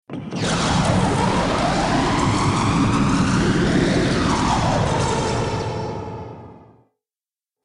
دانلود آهنگ موشک 10 از افکت صوتی حمل و نقل
دانلود صدای موشک 10 از ساعد نیوز با لینک مستقیم و کیفیت بالا
جلوه های صوتی